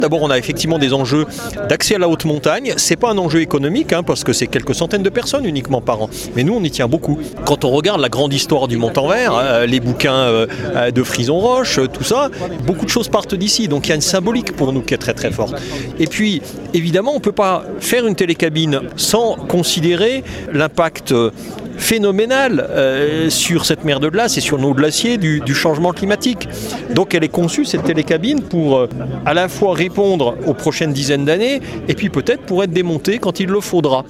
Eric Fournier, le maire de Chamonix :